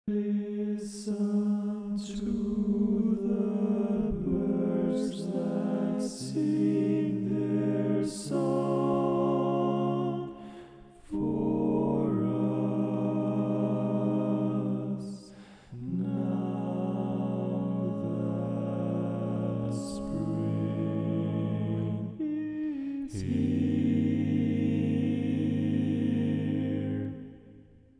Key written in: A♭ Major
How many parts: 4
Type: Barbershop
All Parts mix: